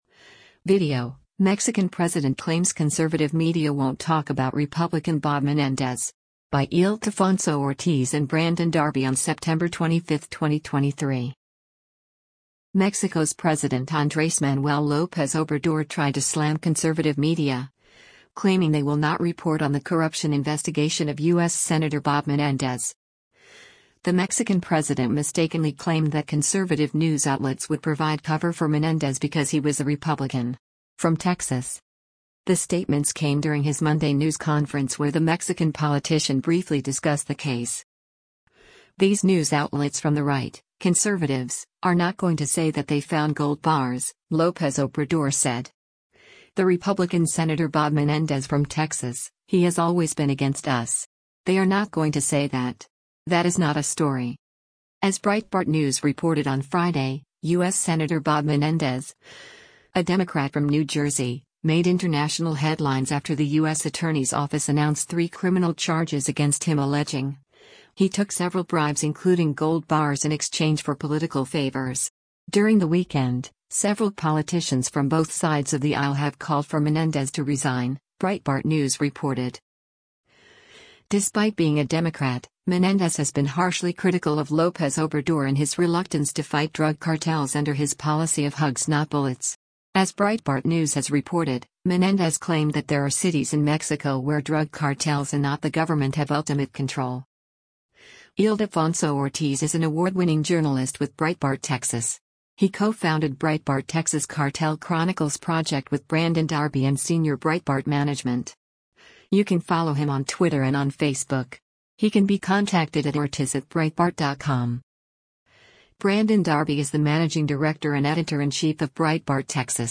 Mexico's President Andres Manuel Lopez Obrador speaks during his daily morning press confe
The statements came during his Monday news conference where the Mexican politician briefly discussed the case.